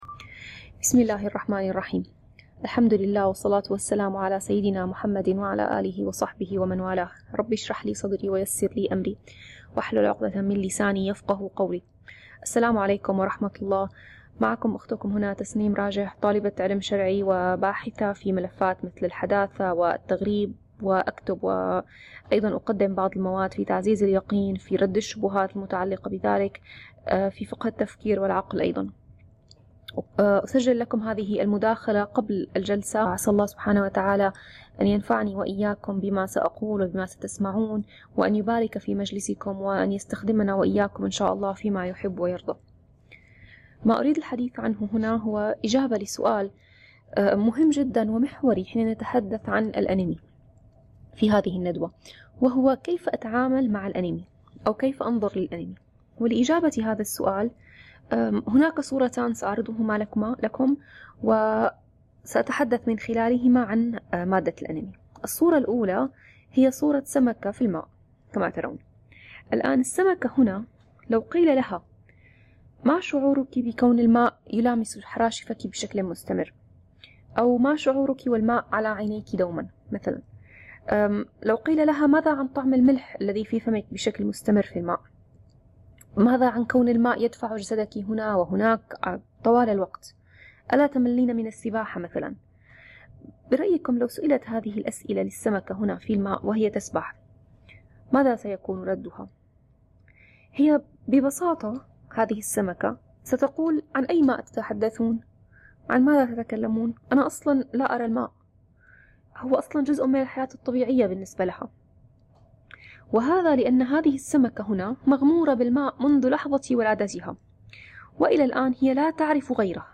صوت-مداخلة-الأنمي.mp3